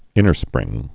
(ĭnər-sprĭng)